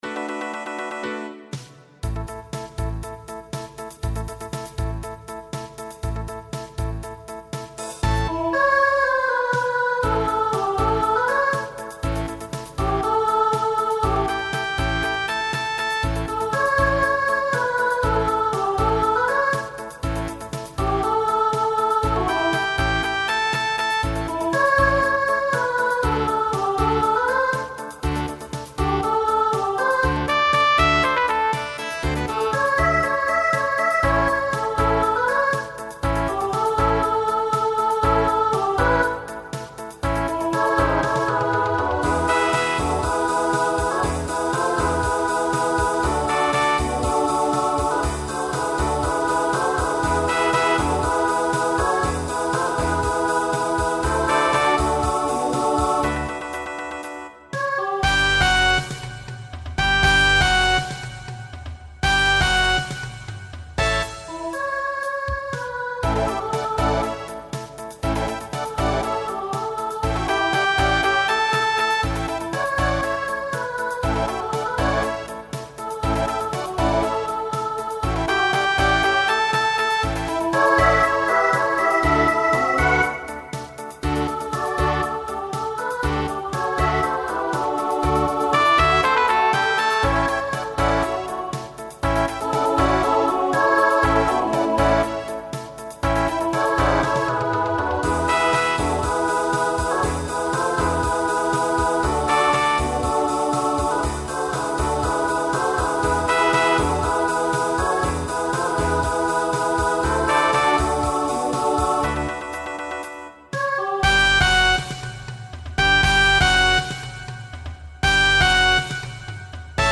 Voicing SSA Instrumental combo Genre Pop/Dance , Rock